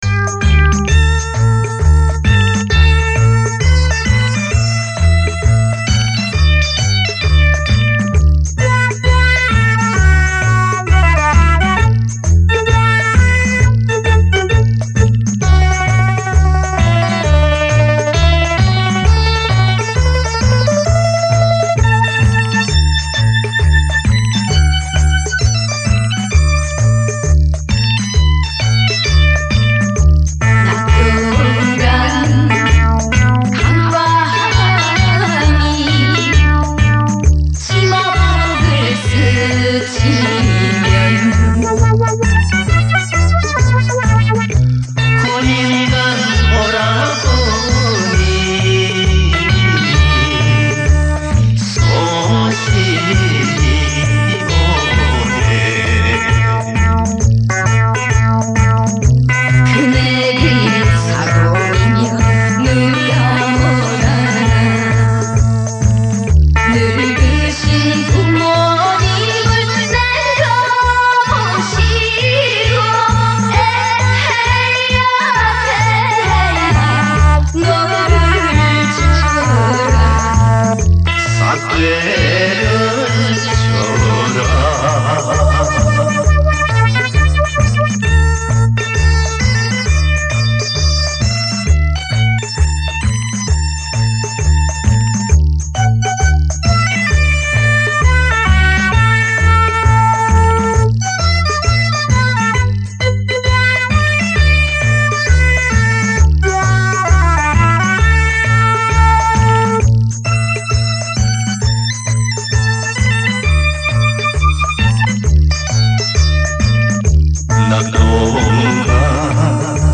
先截一小段联唱（80年代以前的调都好听） 激动社区，陪你一起慢慢变老！